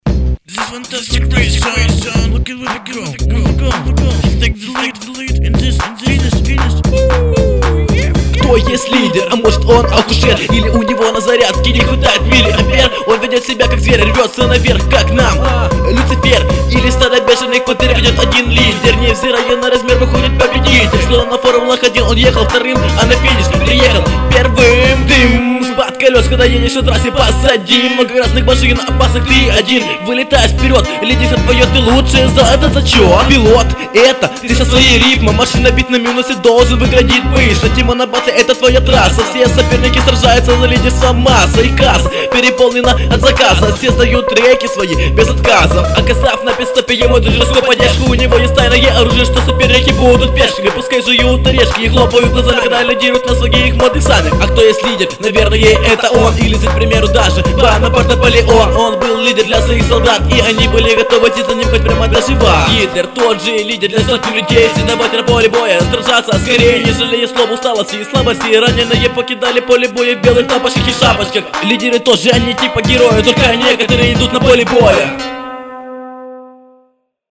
Пытаешься быстро зачитать, а читка так себе, остается впечатление , что боишься сбиться